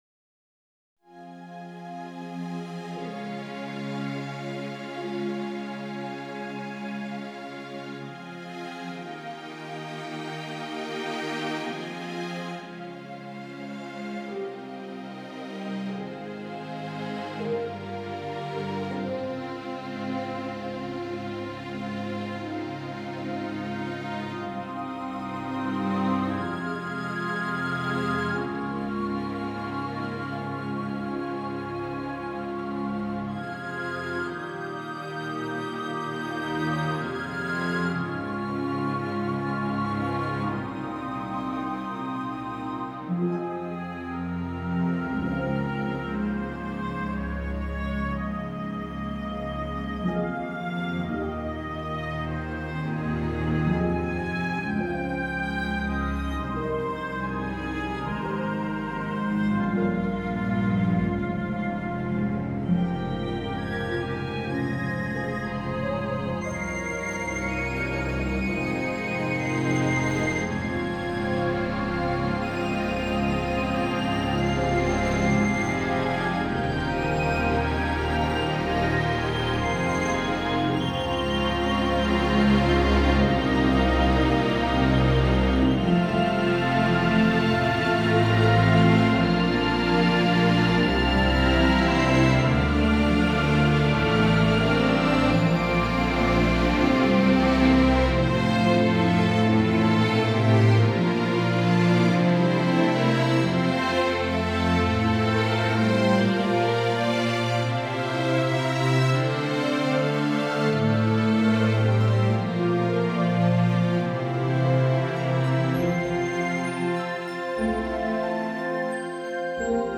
For a small Symphony Orchestra
The music is kept very simple.